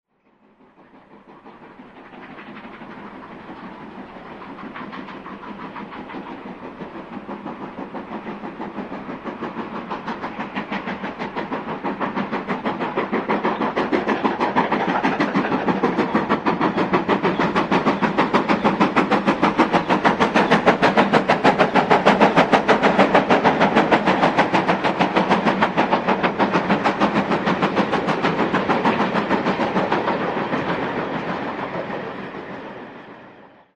This third volume of tracks are all recordings of steam hauled trains on the main line heard from the lineside not all of which have appeared on the web site.